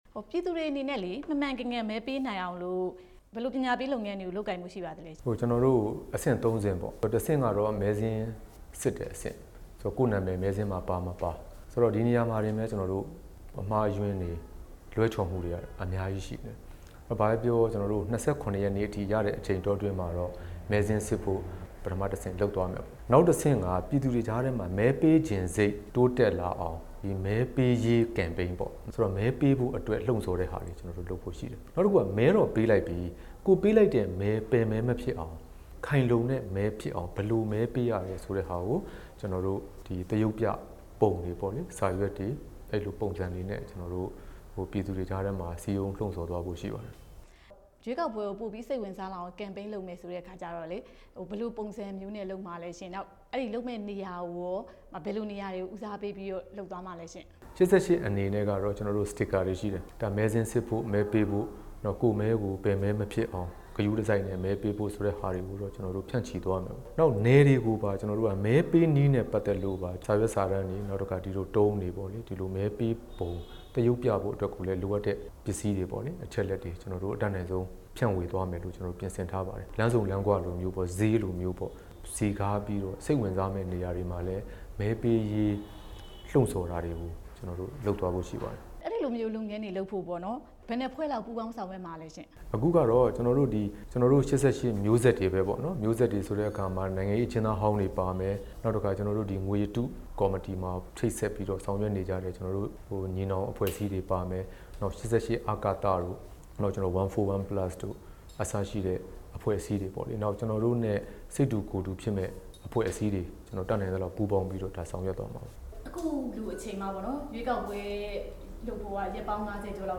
ဦးကိုကိုကြီးနဲ့ မေးမြန်းချက်